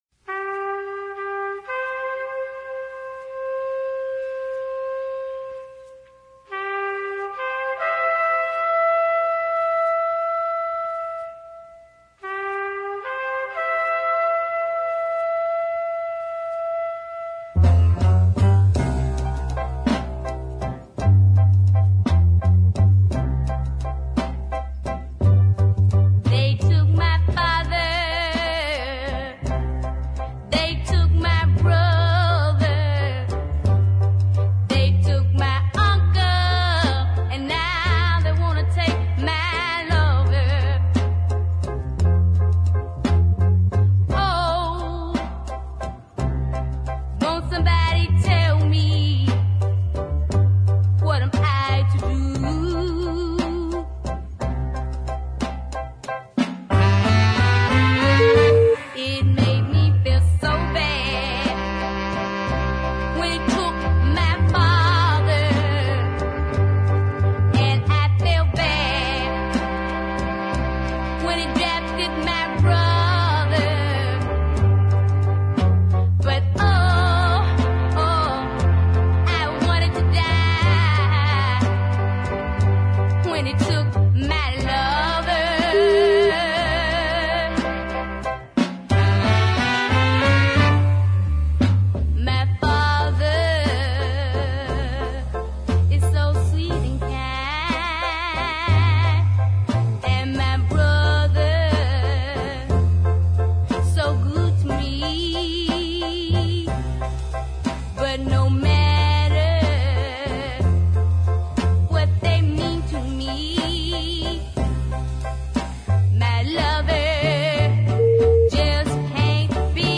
mournful
including the "military" trumpet
"pumping" piano well to the fore and a spirited horn section